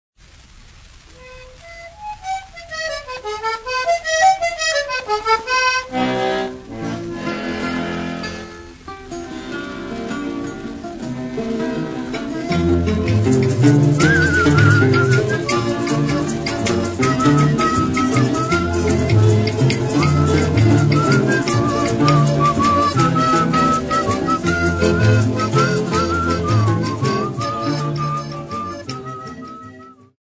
Popular music--Africa
Dance music--Caribbean Area
sound recording-musical
Rumba dance song accompanied by various instruments
96000Hz 24Bit Stereo